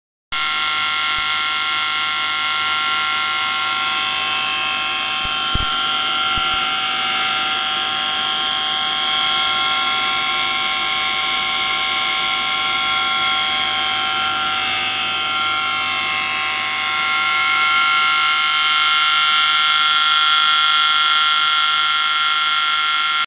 Listen to the noise recorded from the bearing in a motor with an outer ring defect.
Alten-SKF-motor-noise.wav